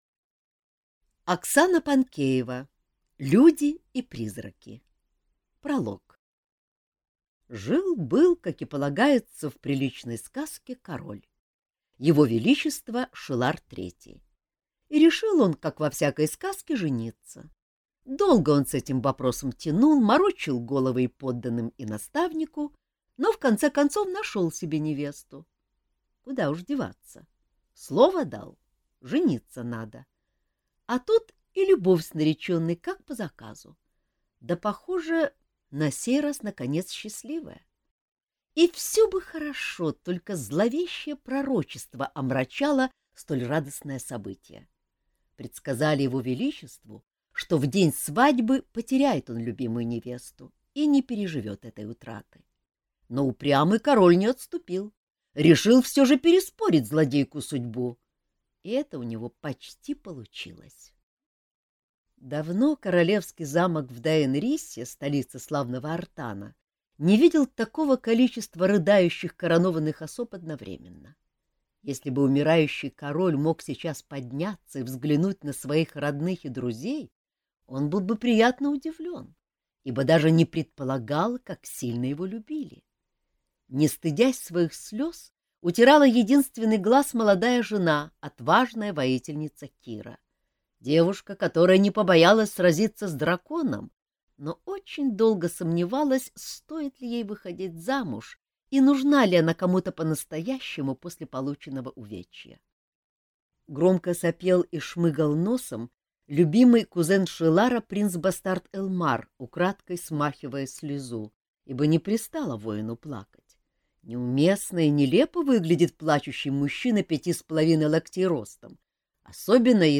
Аудиокнига Люди и призраки | Библиотека аудиокниг